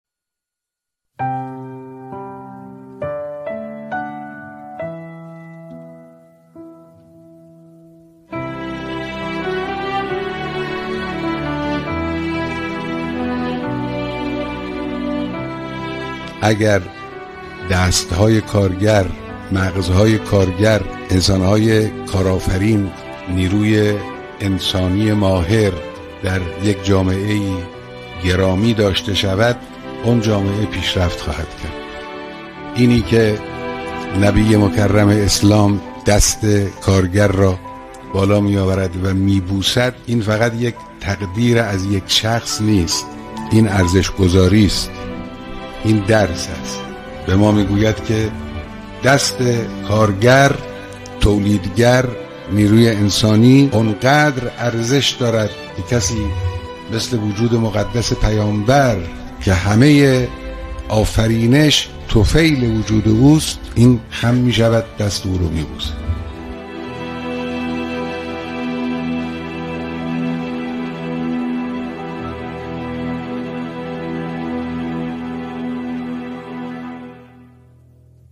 دکلمه‌های زیبا درباره کارگر
دکلمه شماره دو